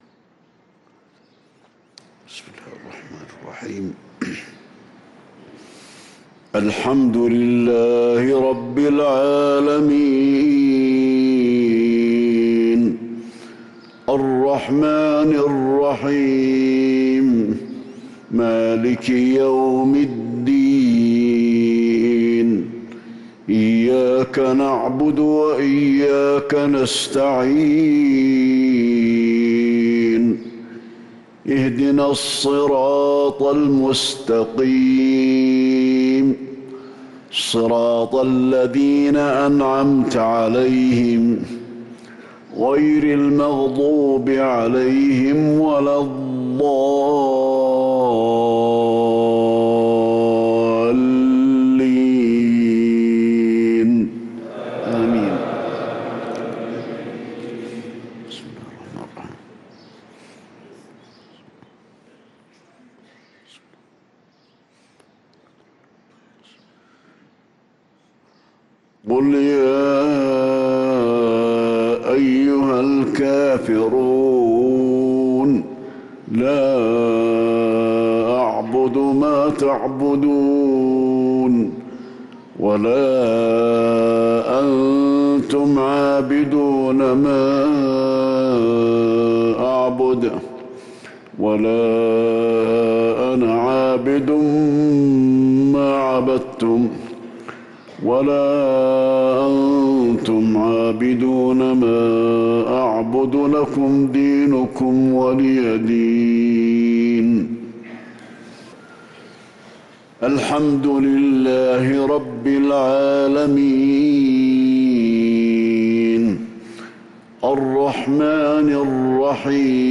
صلاة المغرب للقارئ علي الحذيفي 22 ربيع الأول 1445 هـ
تِلَاوَات الْحَرَمَيْن .